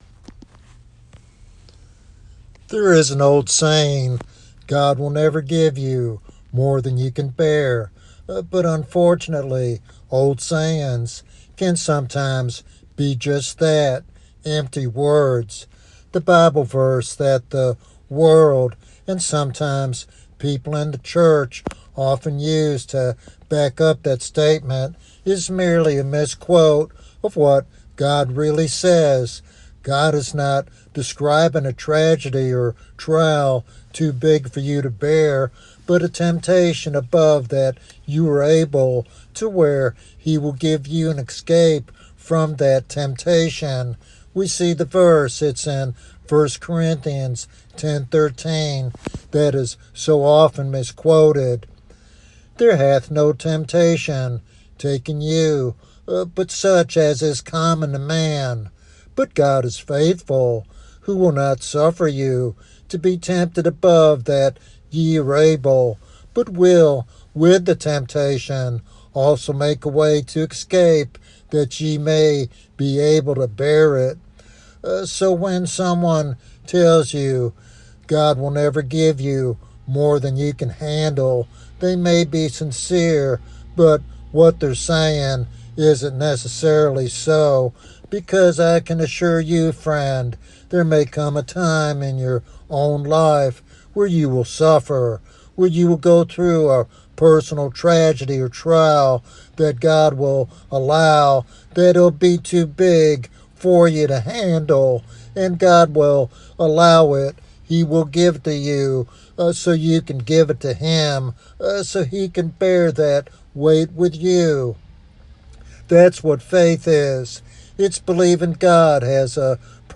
In this heartfelt devotional sermon